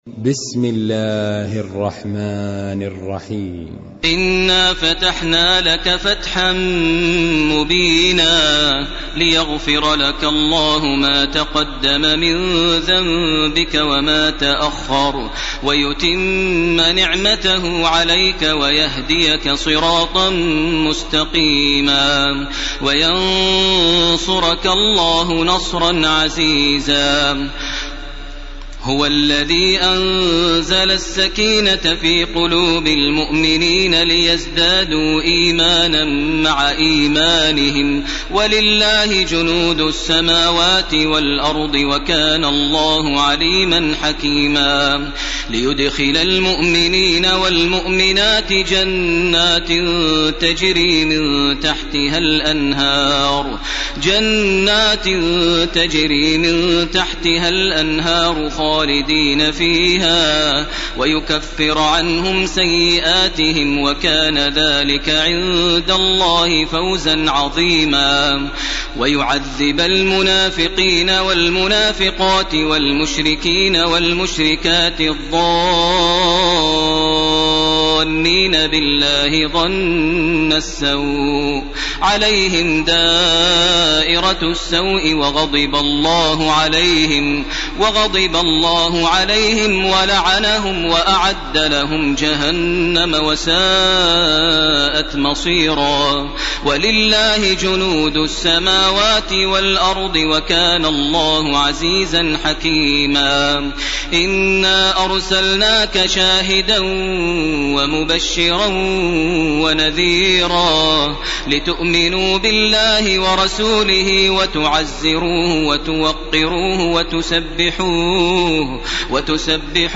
تراويح ليلة 25 رمضان 1431هـ من سور الفتح الى الذاريات Taraweeh 25 st night Ramadan 1431H from Surah Al-Fath to Adh-Dhaariyat > تراويح الحرم المكي عام 1431 🕋 > التراويح - تلاوات الحرمين